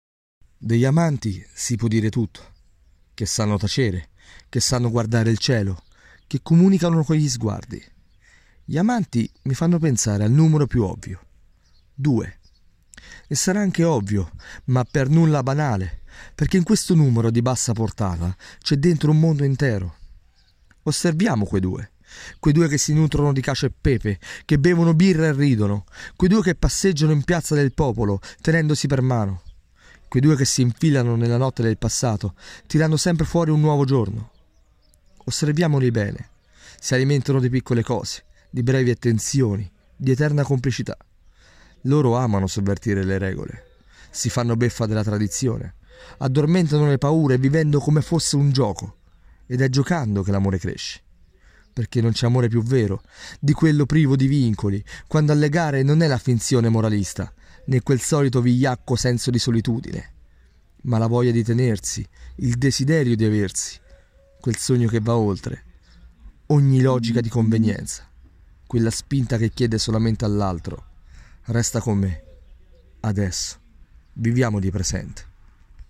Audio poesia “Piazza del Popolo”